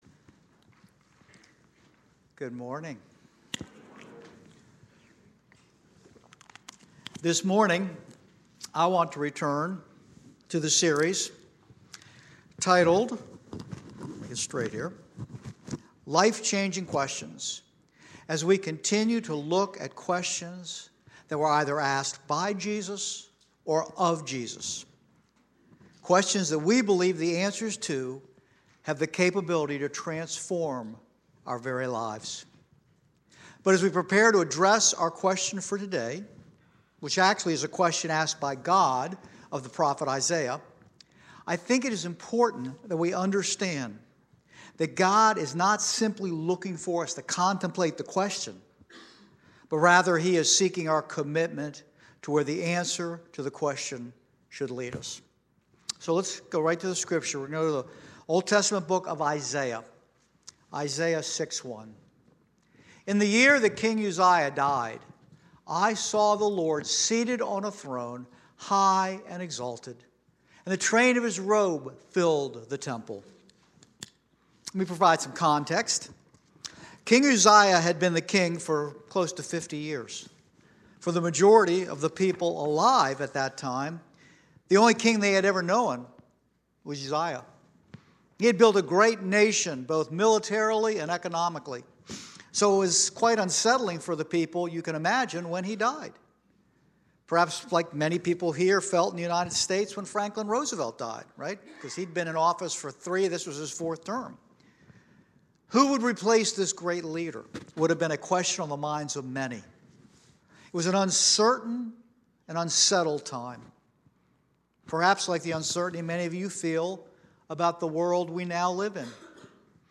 10:30 Service
Sermon